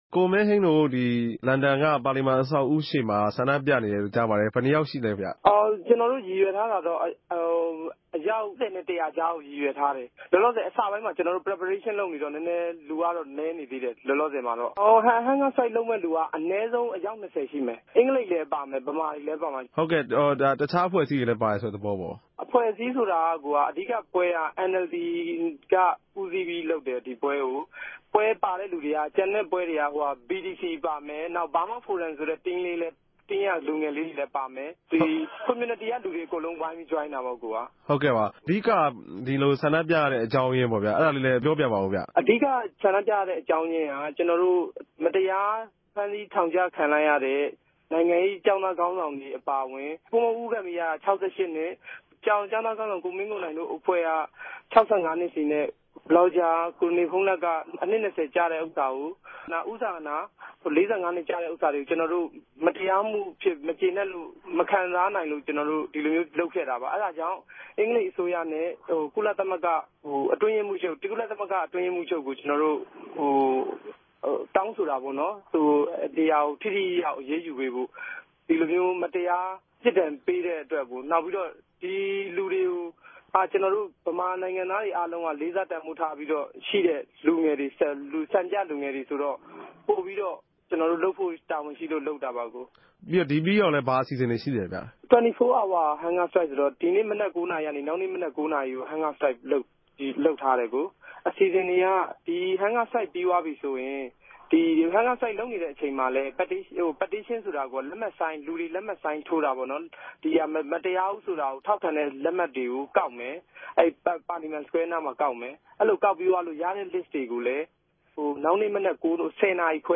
အစာငတ်ခံဆ္ဋိံူပပြဲသတင်း။